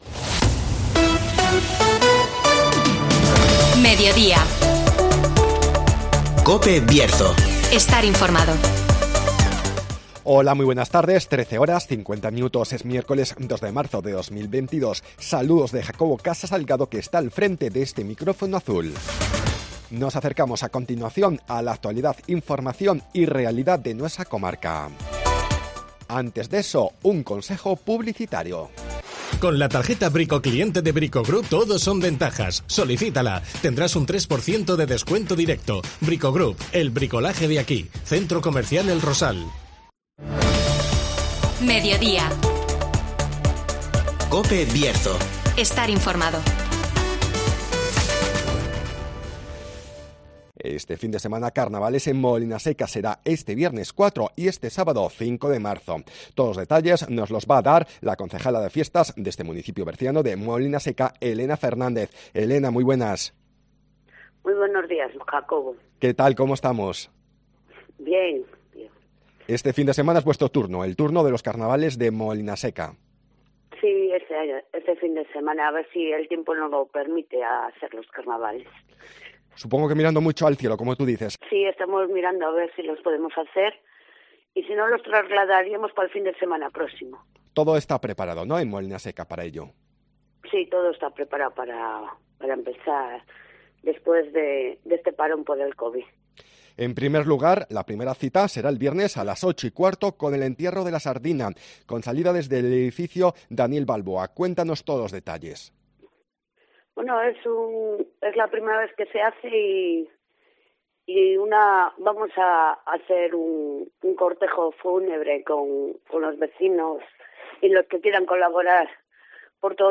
Este fin de semana, Carnavales en Molinaseca (Entrevista a Elena Fernández, concejala de Fiestas)